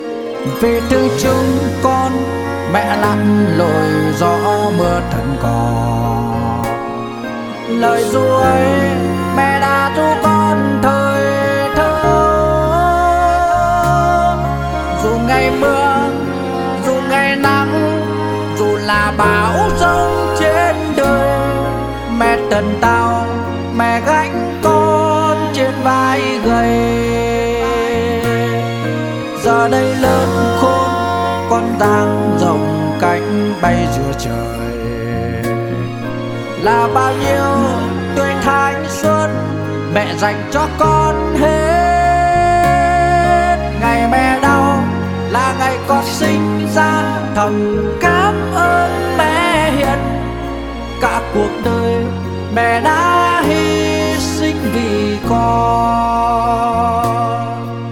Nhạc Trữ Tình.